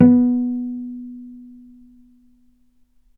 vc_pz-B3-mf.AIF